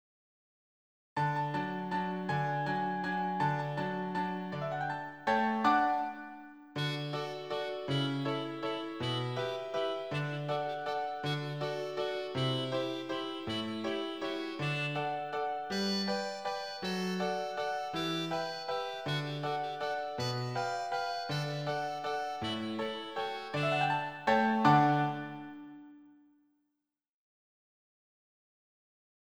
挿入歌４